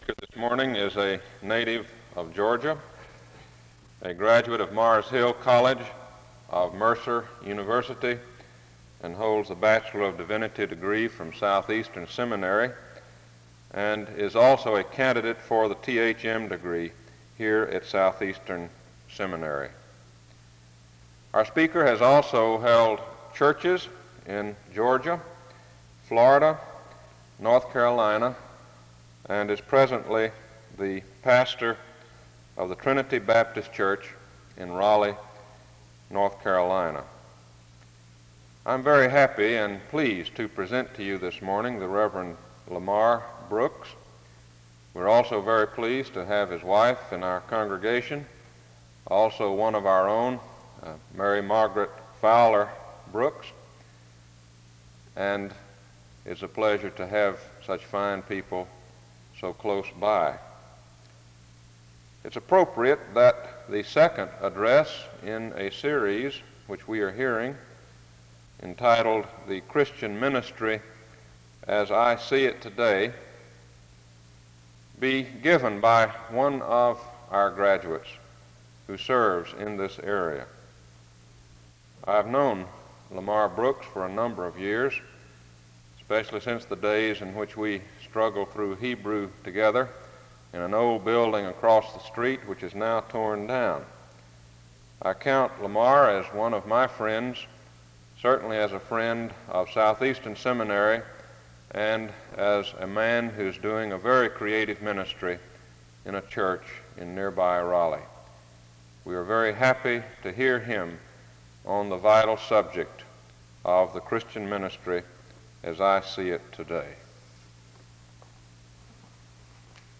The service starts with an introduction to the speaker from 0:00-1:57.